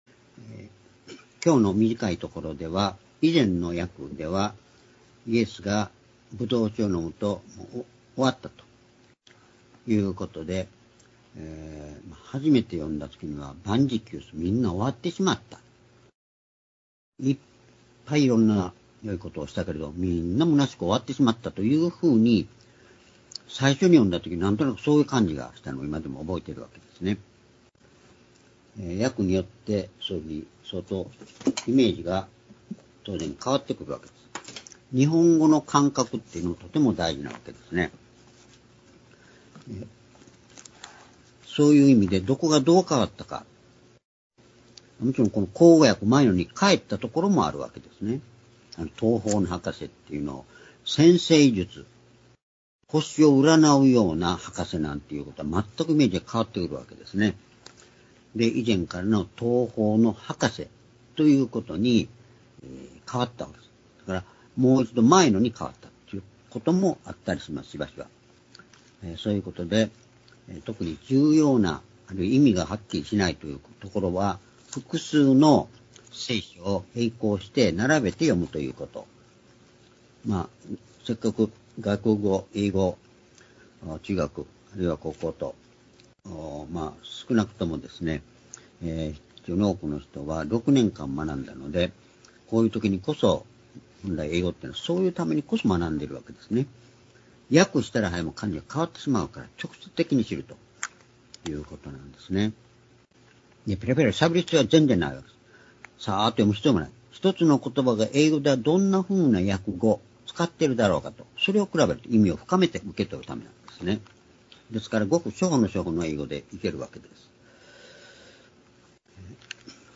「成し遂げられたこととは何か｣ヨハネ19章28～30-2025年2月16日(主日礼拝)